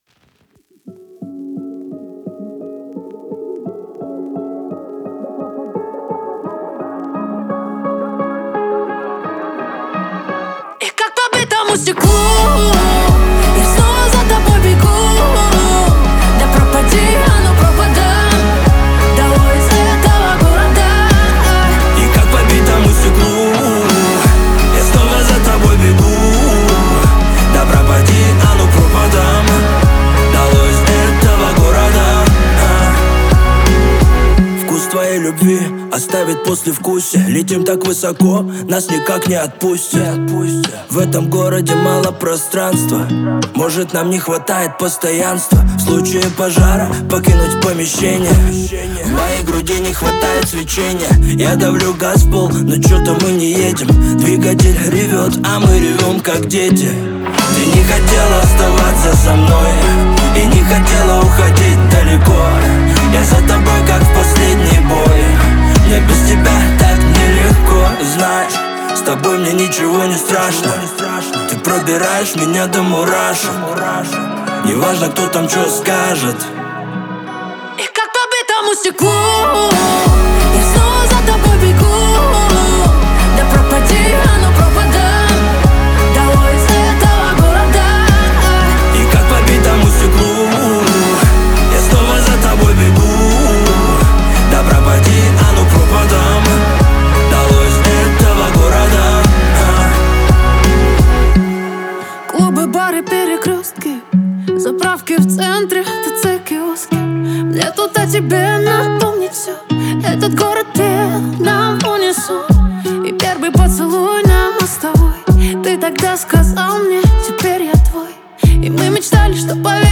• Жанр: Узбекские песни